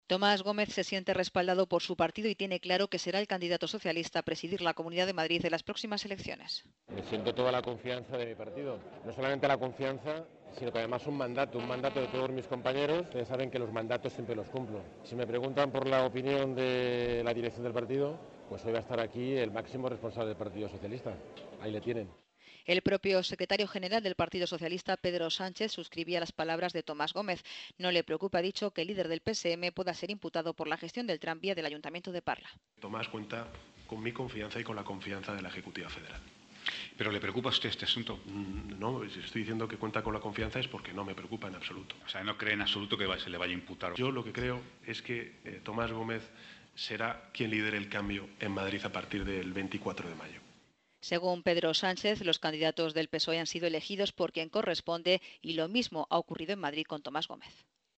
"Tengo toda la confianza del partido. No solamente la confianza, sino además un mandato, un mandato de todos mis compañeros, y saben que los mandatos siempre los cumplo", ha declarado a los medios antes de escuchar al secretario general del PSOE, Pedro Sánchez, en un desayuno informativo de Europa Press.